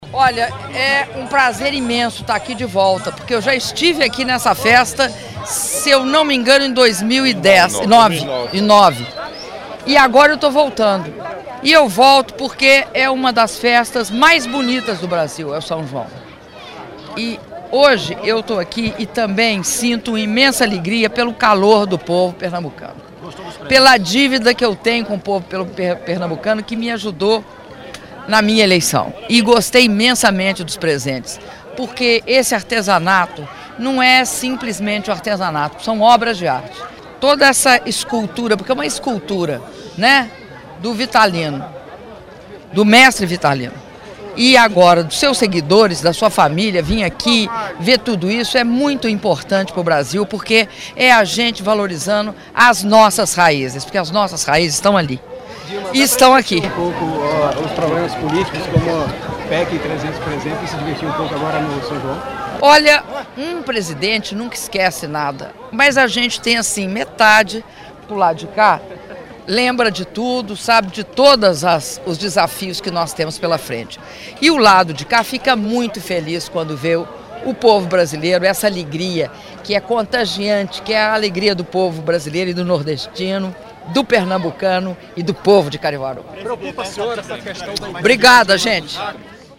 Áudio da entrevista coletiva concedida pela Presidenta da República, Dilma Rousseff, após visita à Casa-Museu Mestre Vitalino (01min29s)